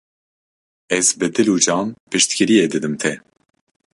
Pronunciado como (IPA)
/d͡ʒɑːn/